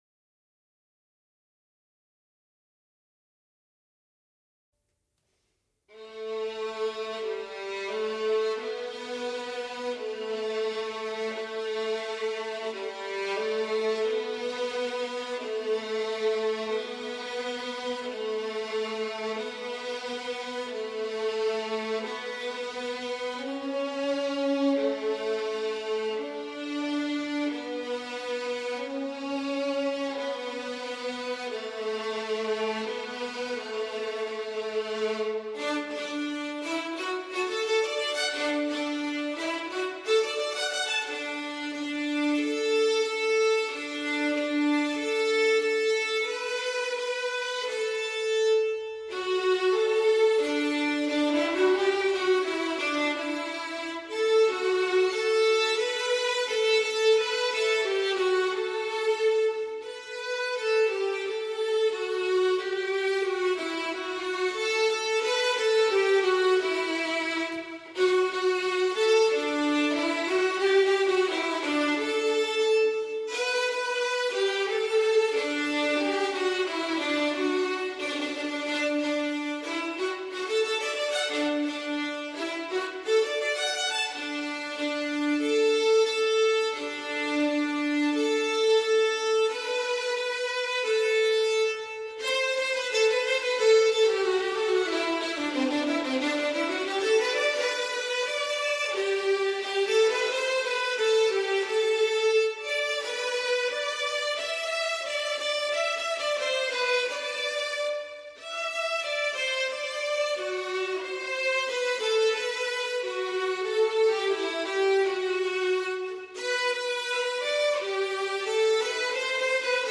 Music for Violin 2
08-Violin-2.mp3